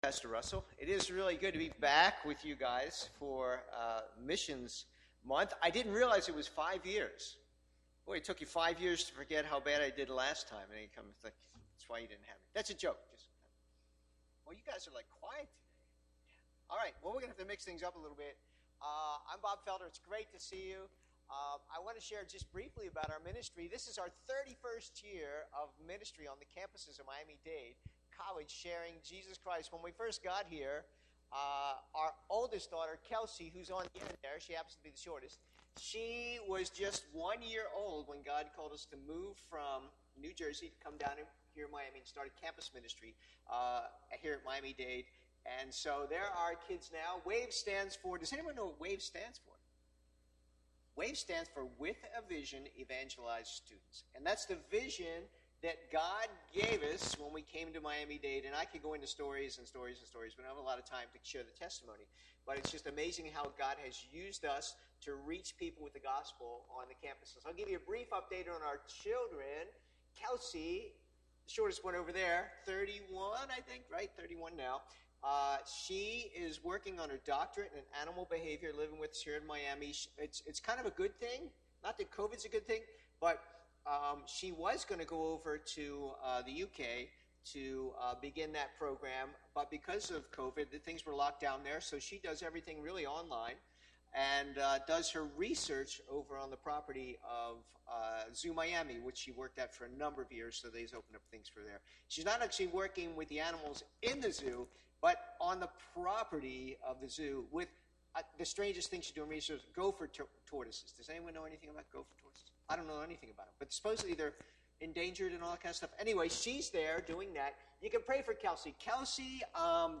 From Series: "English Sermons"